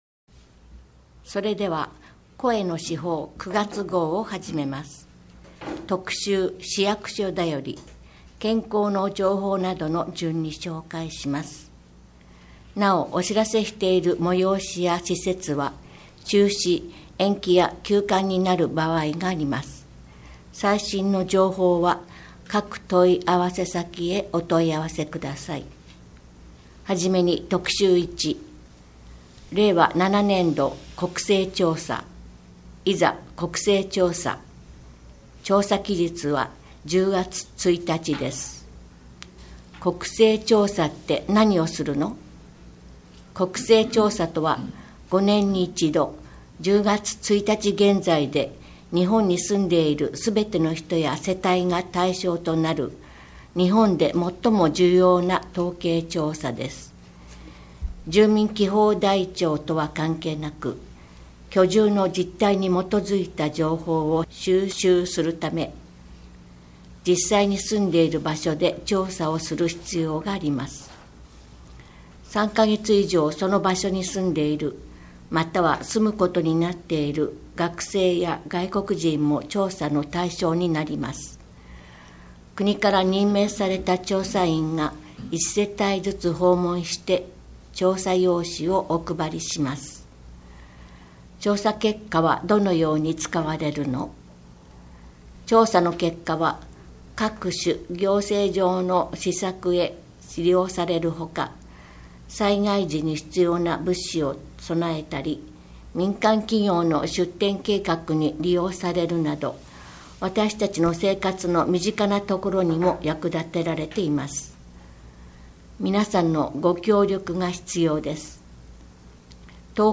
毎月市報べっぷの中から、視覚に障がいがある皆さんに特にお知らせしたい記事などを取り上げ、ボランティアグループ「わたげの会」の皆さんに朗読していただいて作られています。